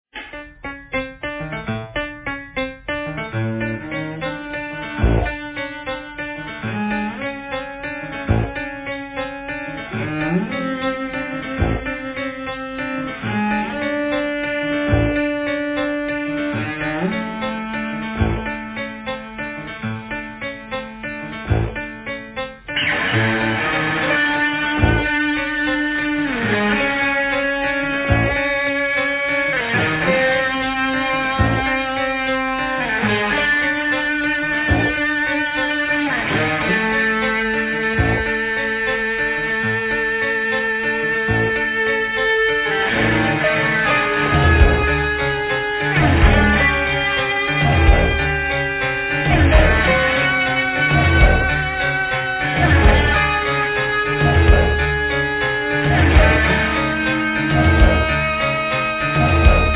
composition, vocals, direction, guitar
drums, percussion, vocals
piano, mellotron, vocals
bass
Cello